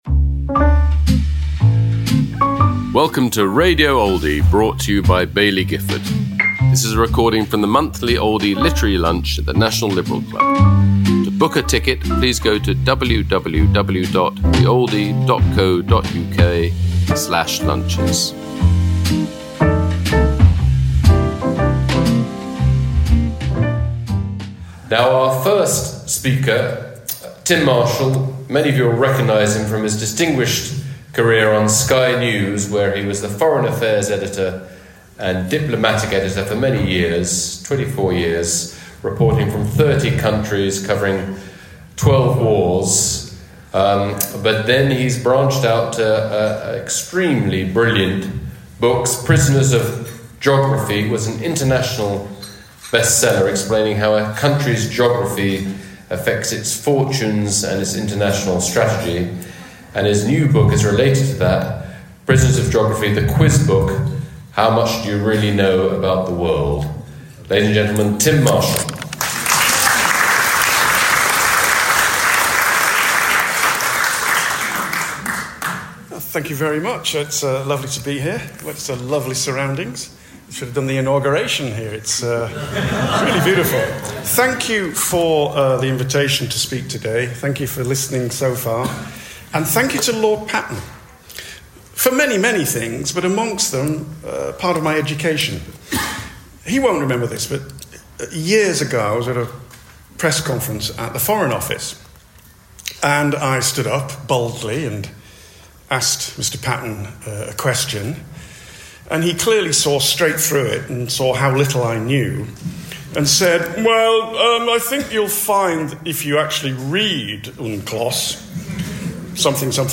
Tim Marshall speaking about his new book, Prisoners Of Geography: the Quiz Book, at the Oldie Literary Lunch, held at London’s National Liberal Club, on January 21st 2025.